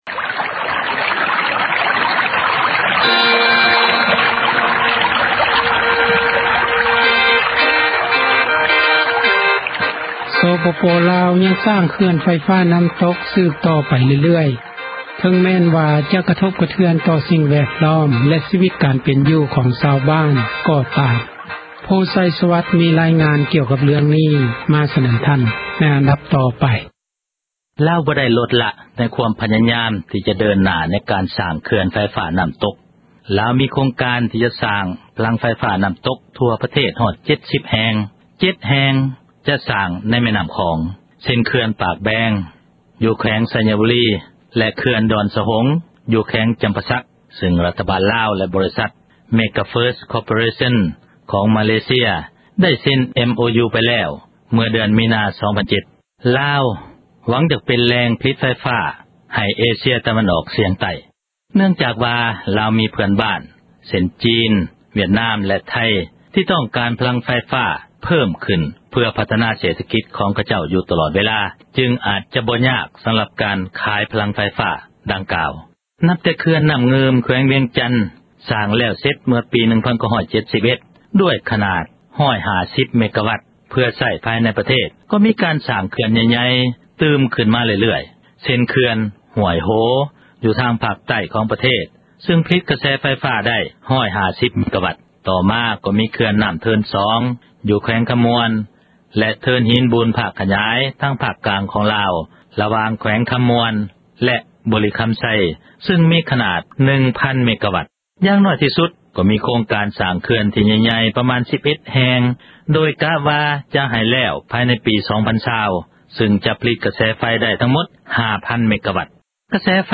ມີຣາຍງານ ມາສເນີທ່ານ ໃນອັນດັບຕໍ່ໄປ...